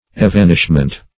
Evanishment \E*van"ish*ment\, n. A vanishing; disappearance.